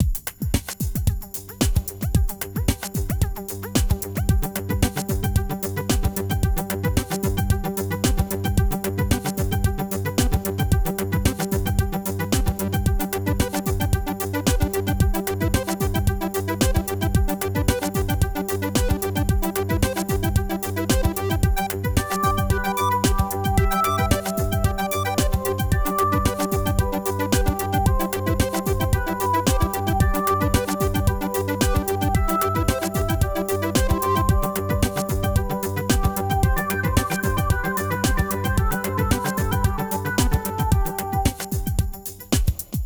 Tecno suave
tecno
melodía
repetitivo
sintetizador
suave
Sonidos: Música